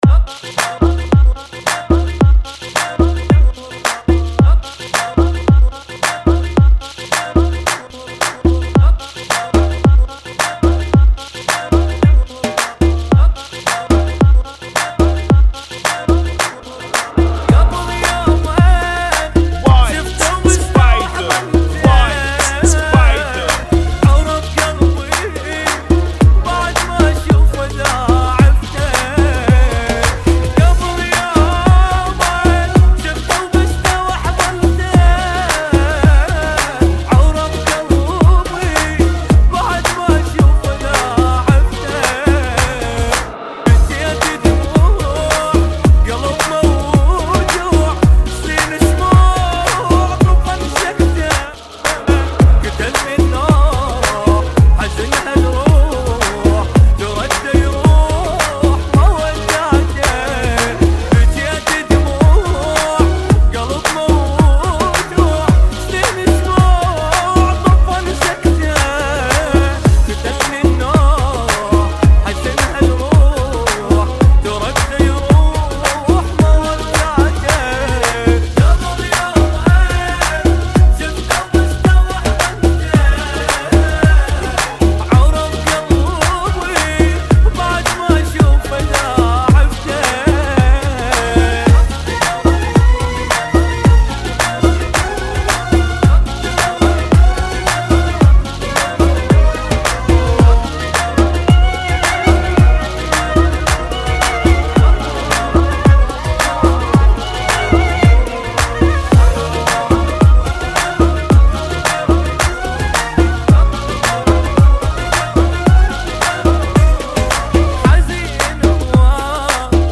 Funky [ 110 Bpm ]